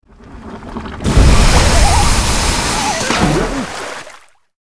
AA_squirt_Geyser.ogg